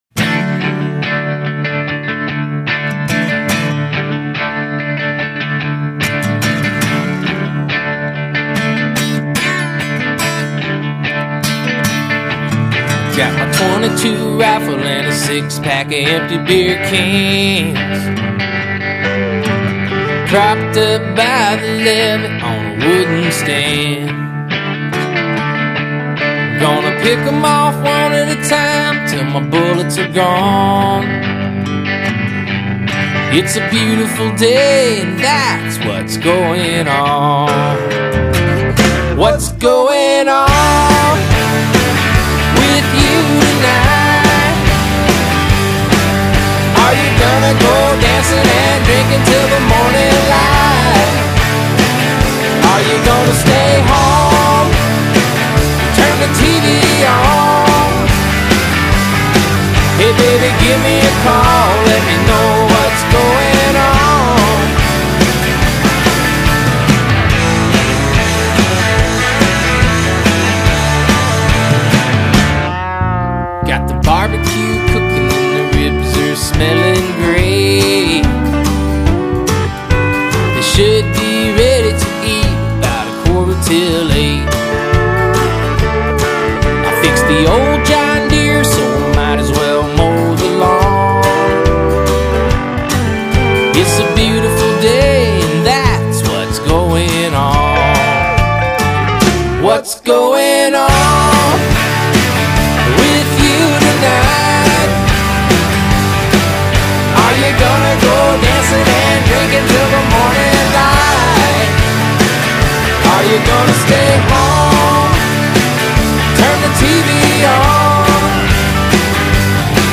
Lead vocal, acoustic guitar, electric guitar
Backing vocals
Bass
Drums
Pedal steel